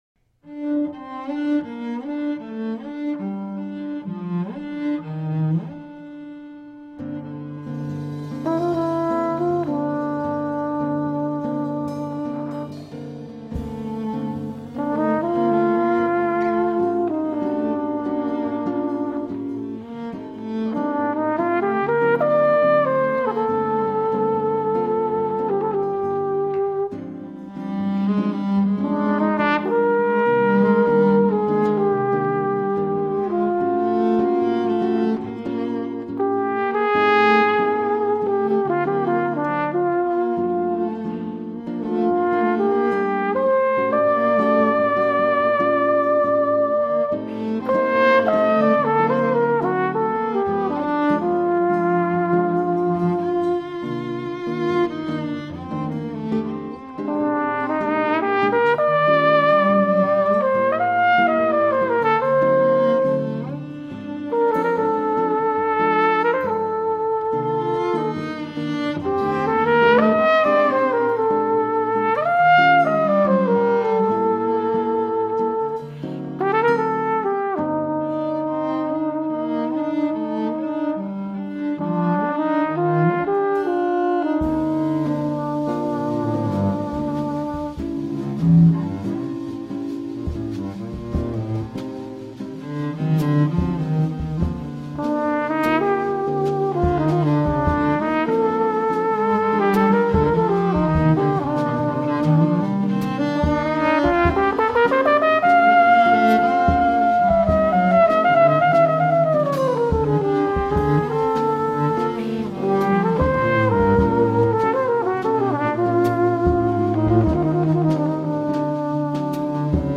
BRASILIAN TRUMPET STUFF
bewerkt voor bugel
(langzaam).